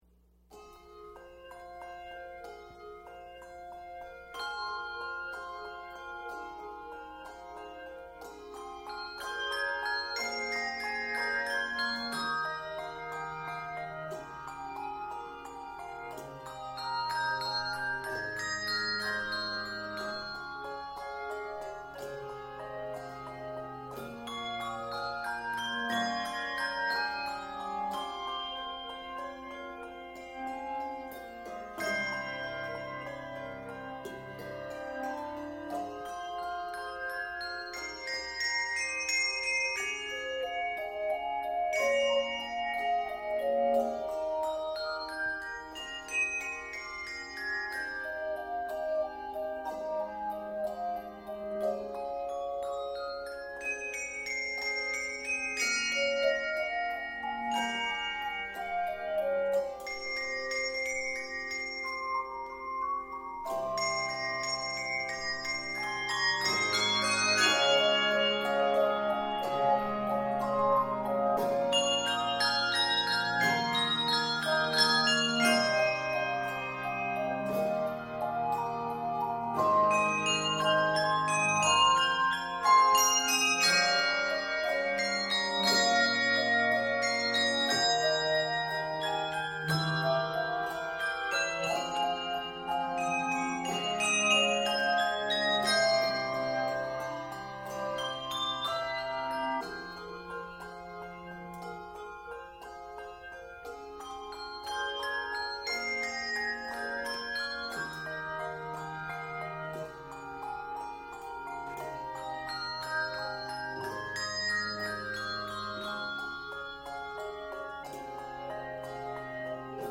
handbells
N/A Octaves: 5-6 Level